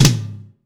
ROOM TOM2B.wav